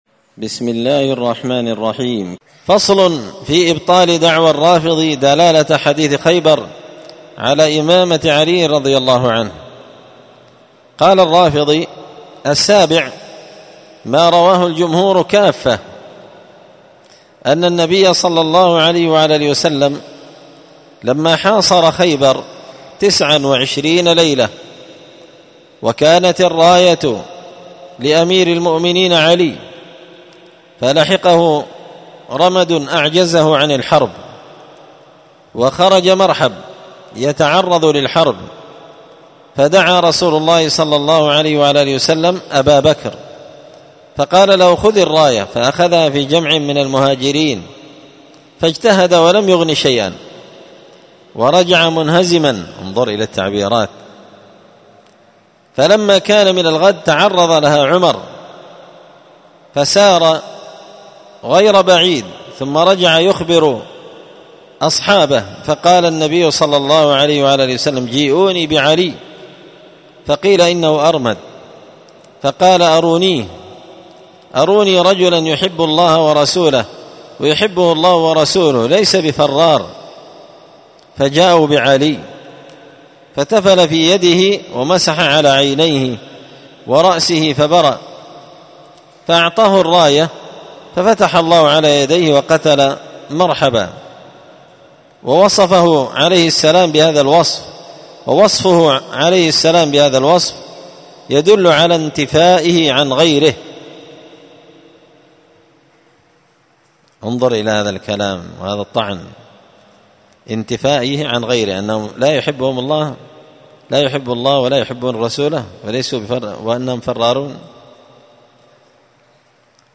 الدرس السابع والتسعون بعد المائة (197) فصل في إبطال دعوى الرافضي دلالة حديث خيبر على إمامة علي